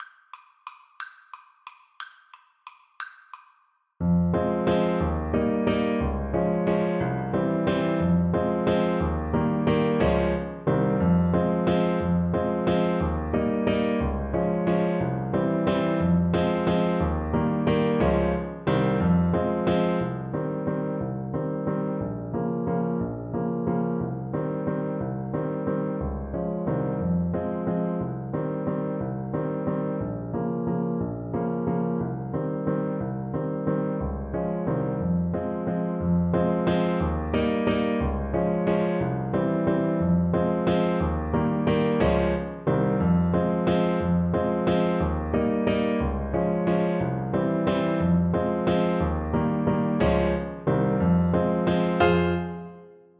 Traditional Trad. Marchoise Limousine-Poitou Alto Saxophone version
F minor (Sounding Pitch) D minor (Alto Saxophone in Eb) (View more F minor Music for Saxophone )
One in a bar . = c. 60
3/4 (View more 3/4 Music)
C5-C6
Traditional (View more Traditional Saxophone Music)
world (View more world Saxophone Music)